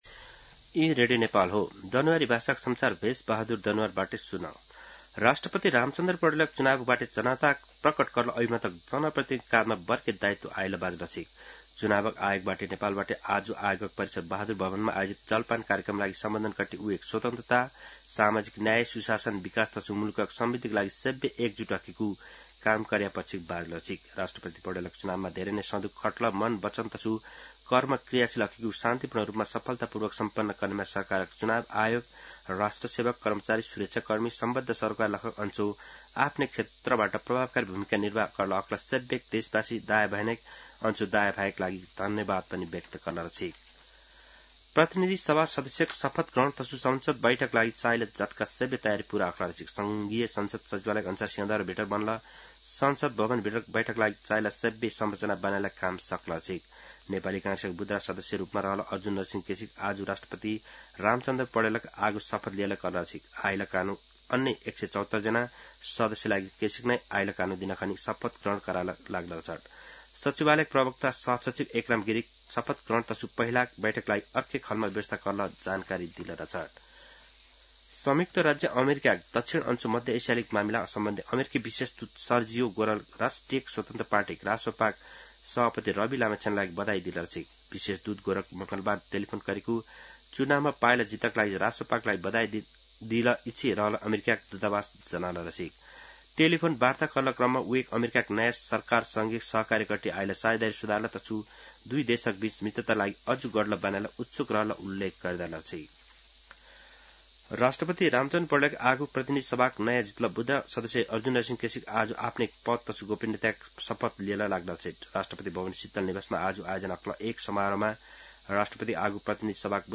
दनुवार भाषामा समाचार : ११ चैत , २०८२
Danuwar-News-11.mp3